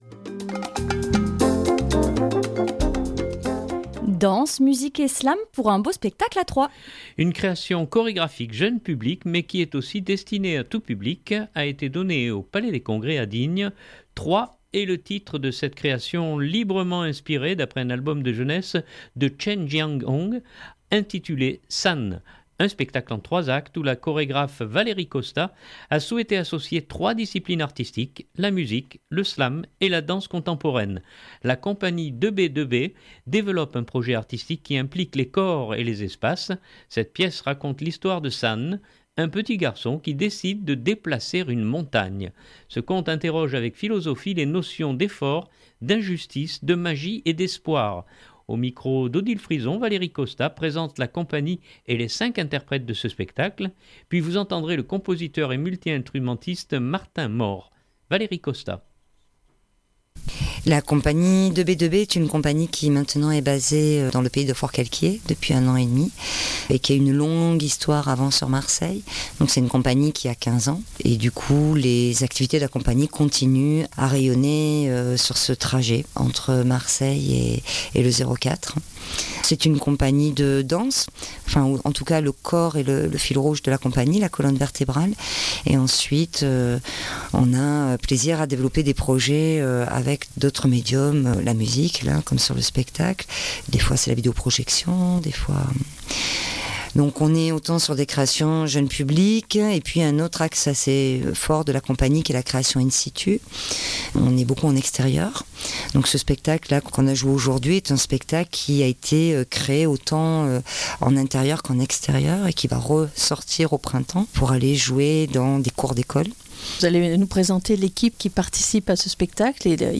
puis vous entendrez le compositeur et multi-instrumentiste